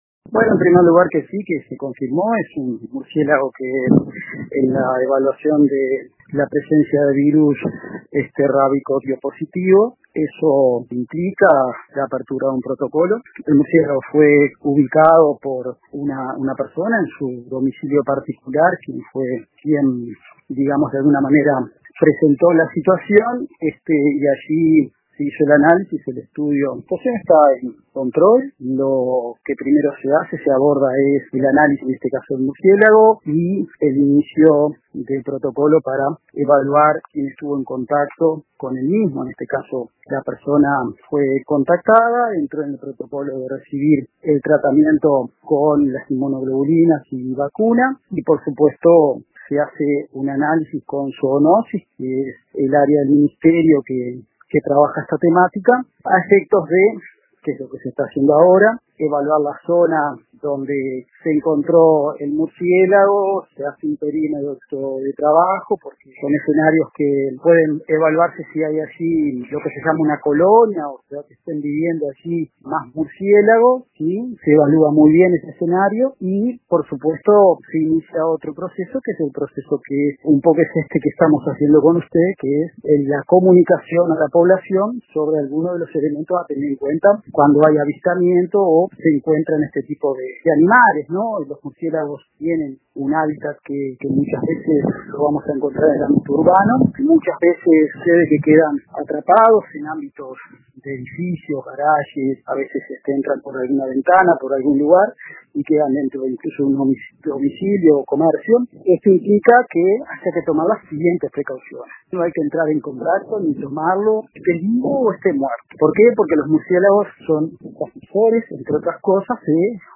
El director departamental de Salud de Maldonado, doctor José González, explicó al Informativo Central de RADIO RBC que el caso activó de inmediato los protocolos sanitarios correspondientes.